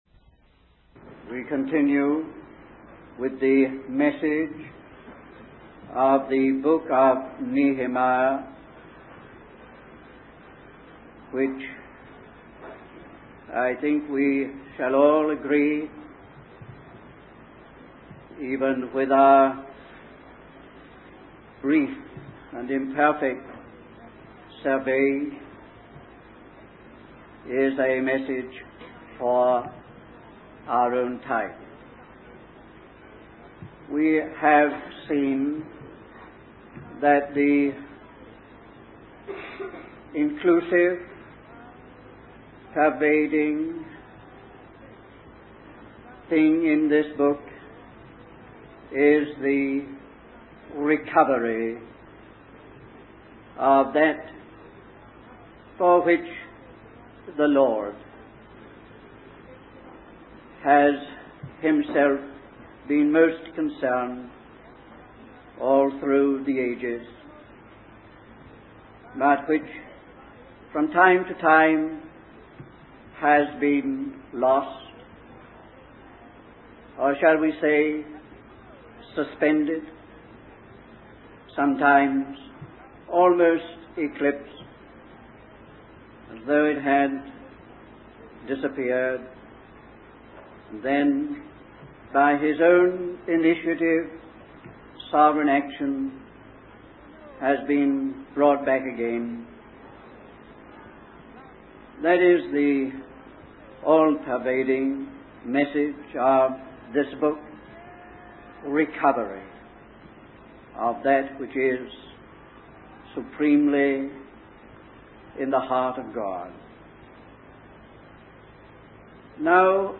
In this sermon, the speaker emphasizes the importance of being committed to God's purpose. He highlights the need for a specific and personal vision of God's plan in the present time.